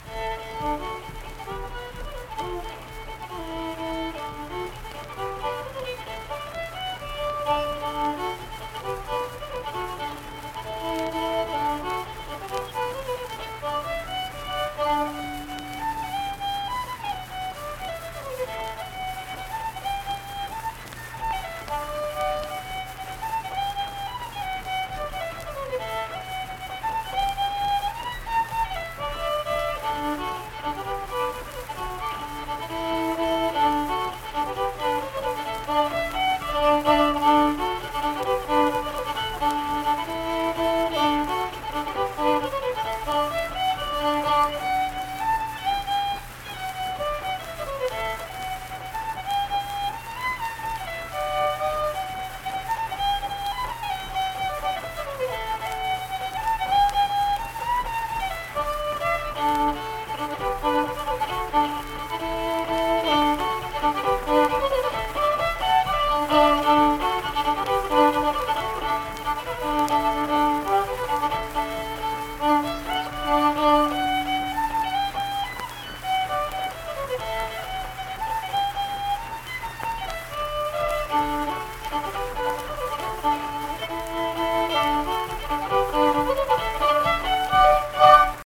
Unaccompanied fiddle music
Verse-refrain 4(2).
Instrumental Music
Fiddle